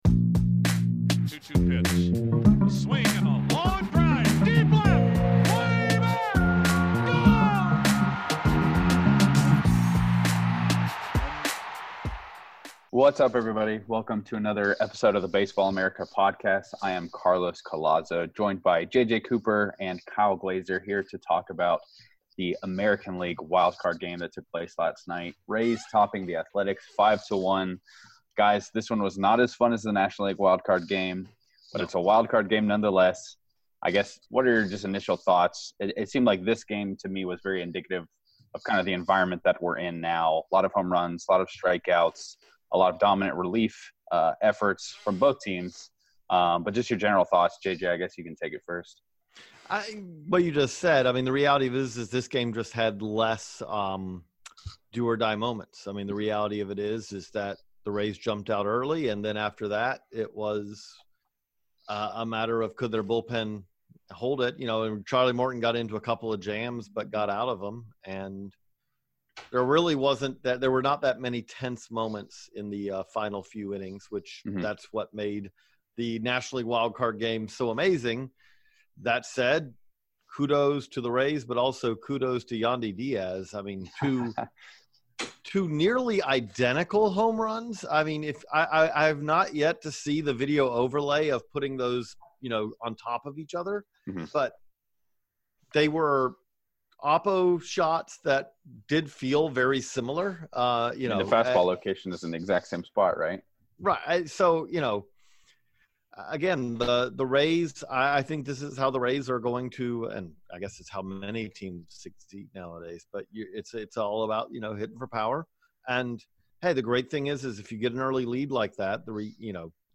We've got a three-man booth on today's playoff pod.